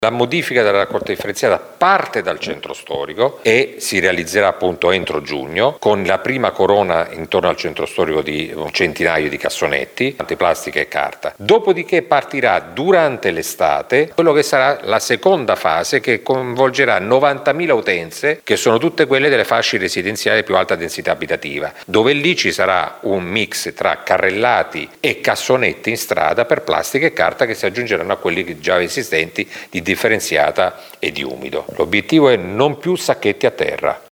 Intanto si lavora per eliminrare il porta a porta e  togliere da terra i sacchetti di carta e plastica:  in estate toccherà ai residenti e alle attività del centro storico poi il nuovo modello, con cassonetti o bidoni carrellati, sarà esteso a tutta la città. Sentiamo ancora il sindaco Mezzetti…